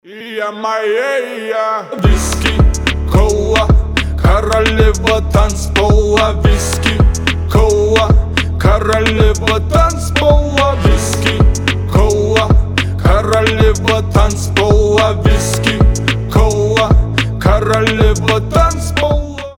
Рэп рингтоны , Танцевальные рингтоны